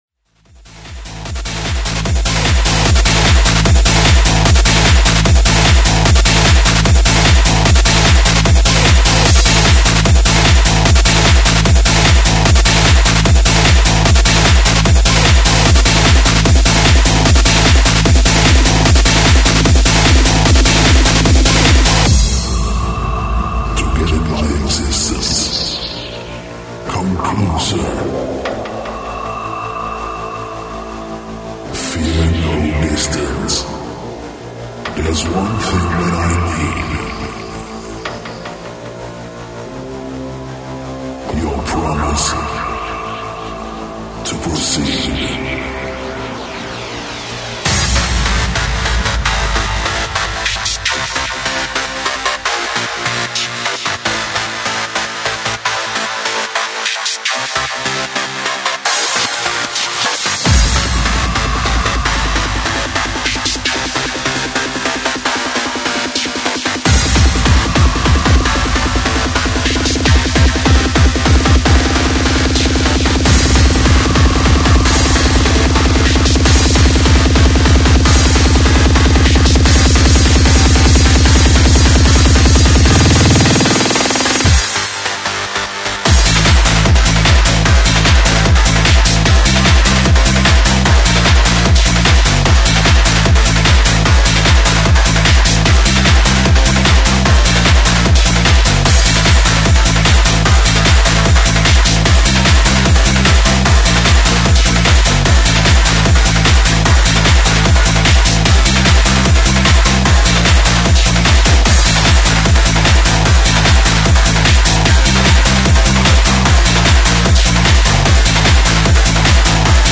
Hard Dance